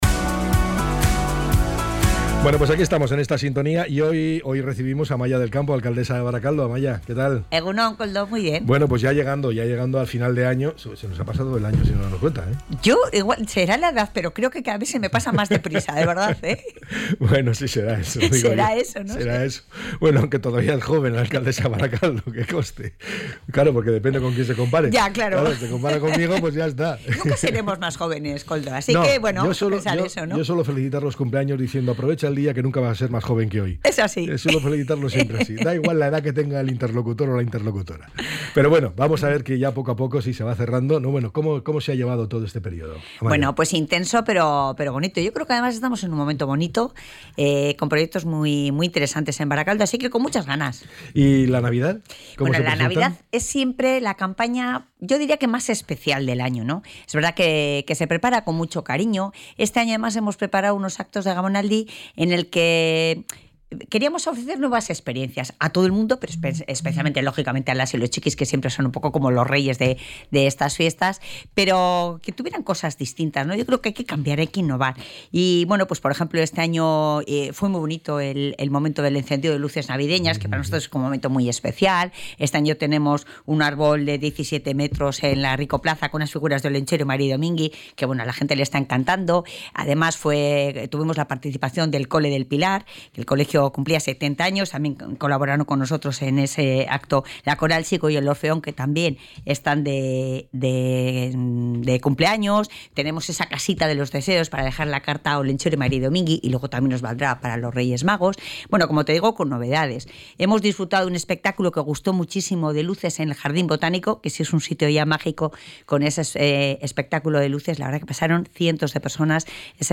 La alcaldesa de Barakaldo, Amaia del Campo, detalla la campaña navideña y las claves del nuevo presupuesto municipal